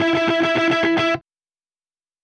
Retro Funkish Guitar Ending.wav